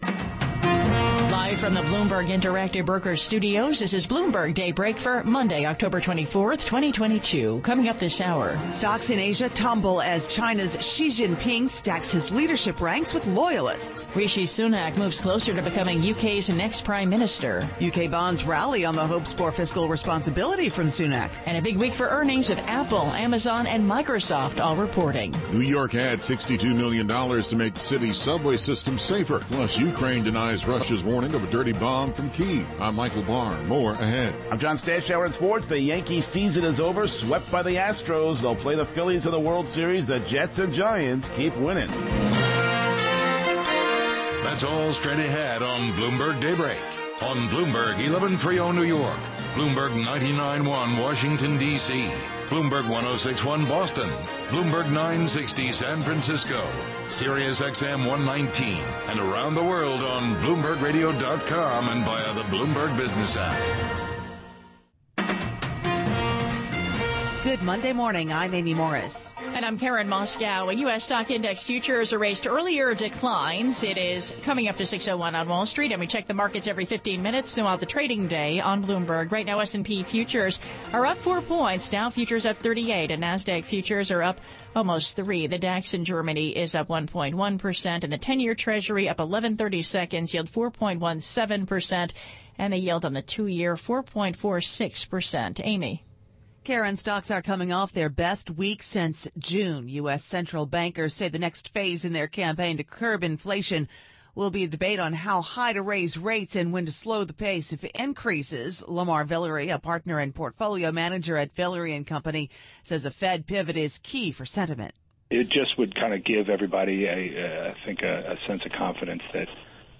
Bloomberg Daybreak: October 24, 2022 - Hour 2 (Radio)